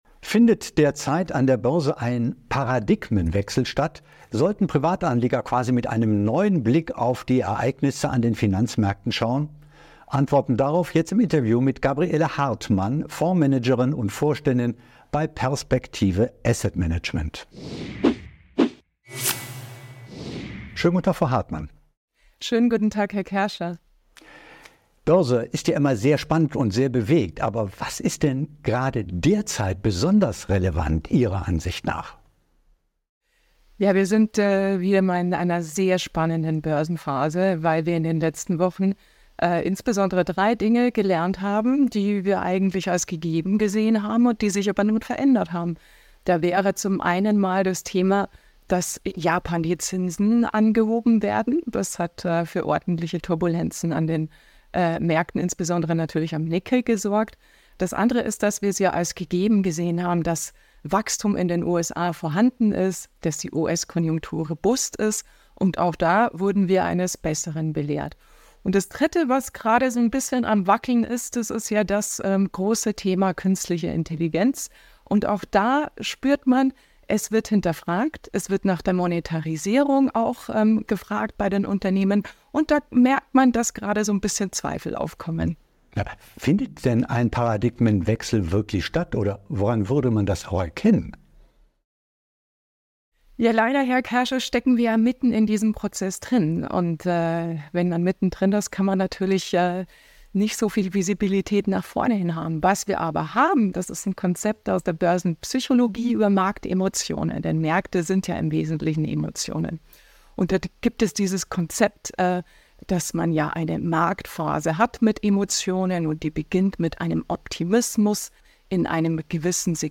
Fundierte Finanzanalysen und exklusive Experteninterviews